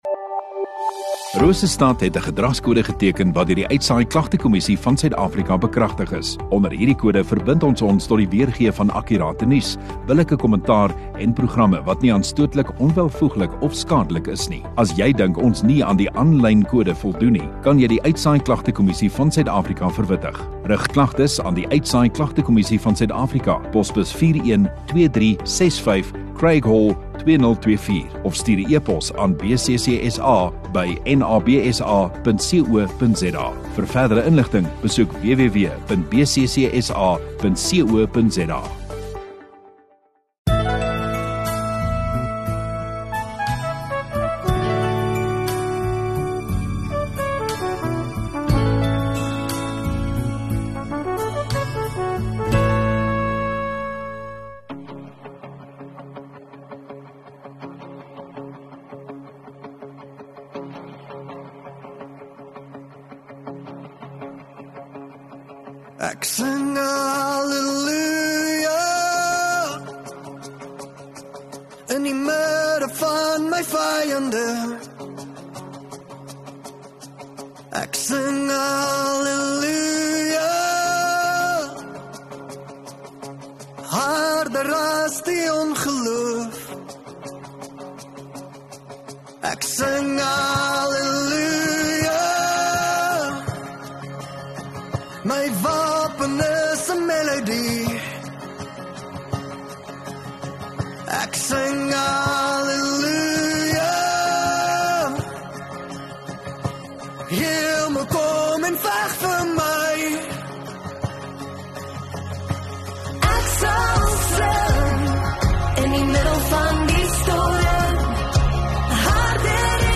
13 Oct Sondagoggend Erediens